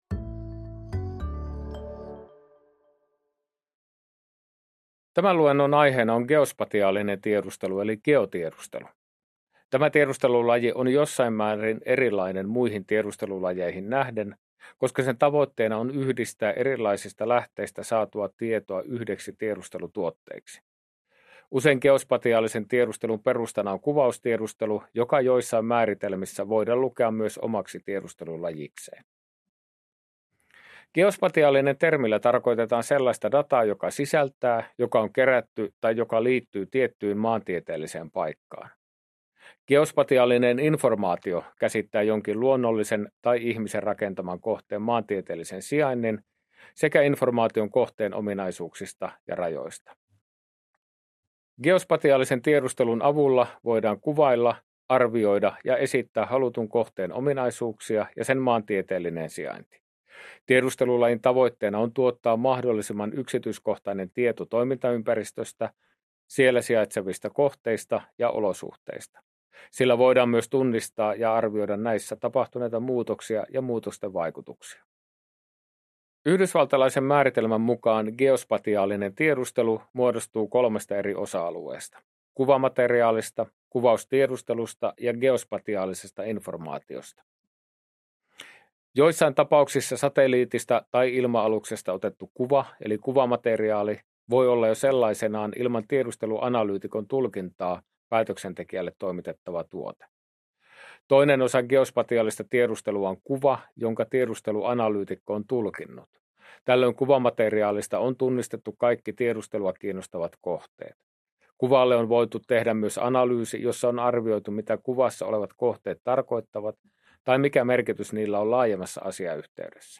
Päivitetty luento lv25-26 toteutukseen